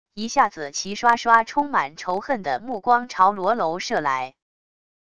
一下子齐刷刷充满仇恨的目光朝罗楼射来wav音频生成系统WAV Audio Player